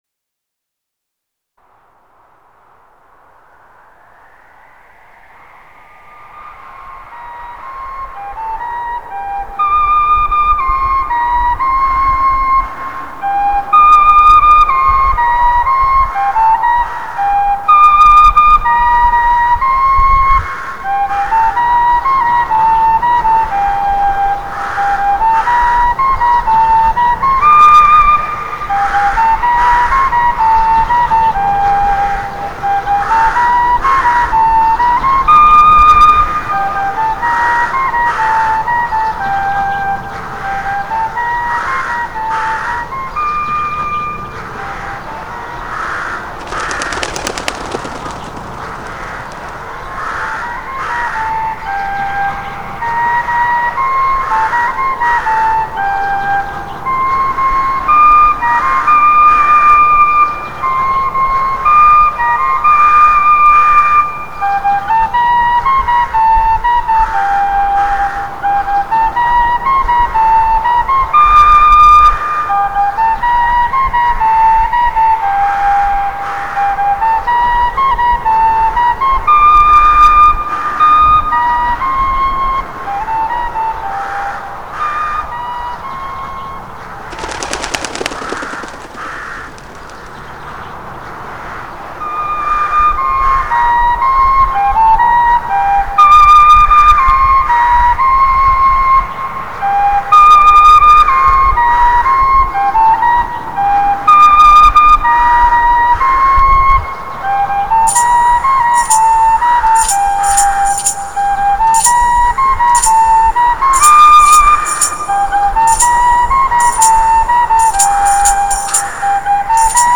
Genre: Medieval Folk / Ambient